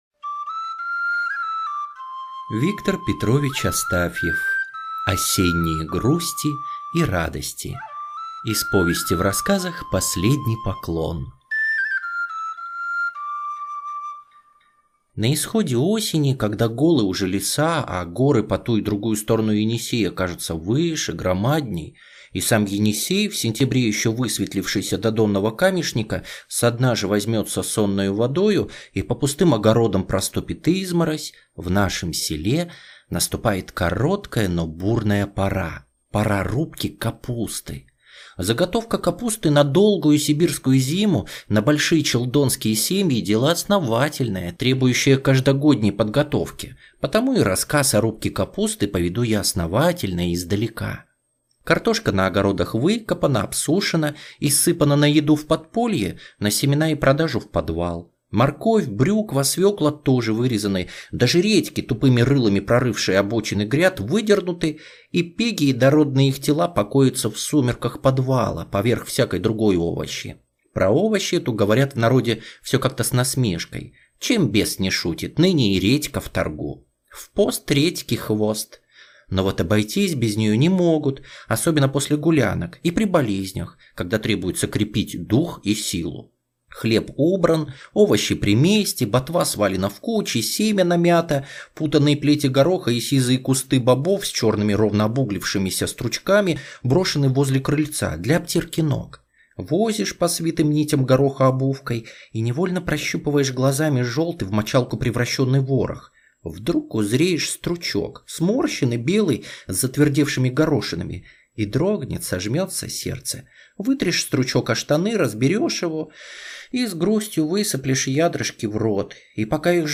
Осенние грусти и радости - аудио рассказ Астафьева В.П. Слушать онлайн о том, как в одном из сибирских сел проходит засолка капусты на зиму.